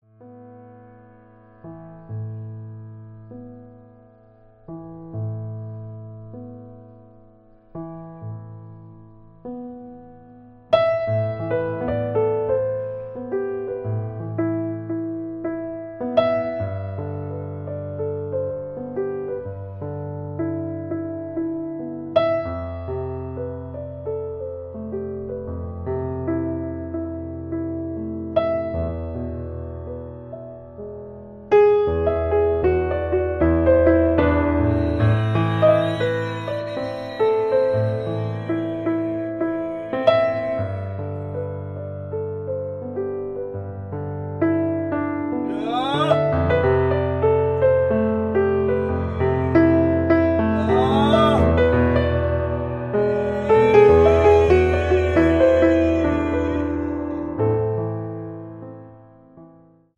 Latin Jazz
pianist